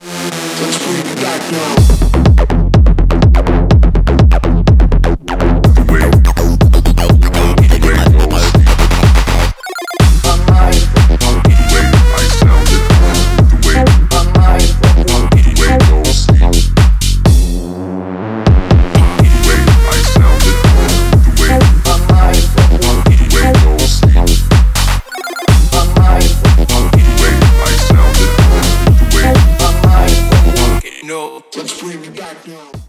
Электроника
клубные # громкие